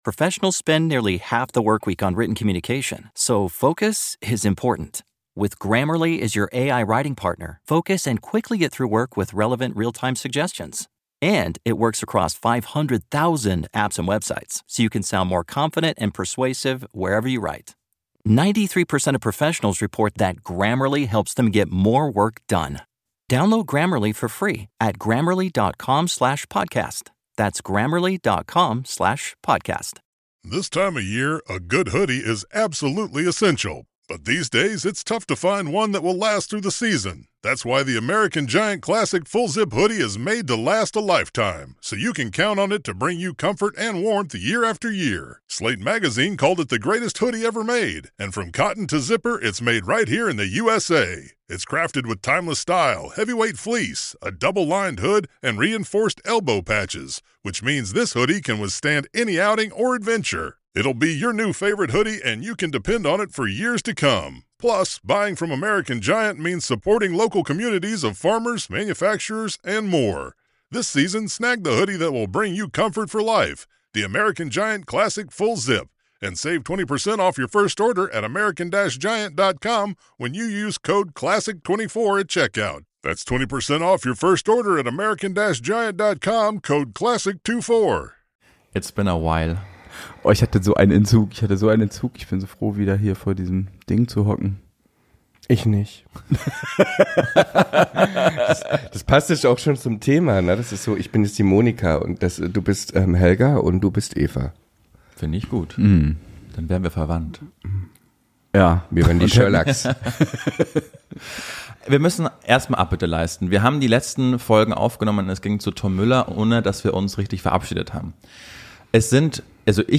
Und um die Spannung noch etwas aufzubauen: Die drei reden über ihre kulturellen Highlights in diesem Jahr.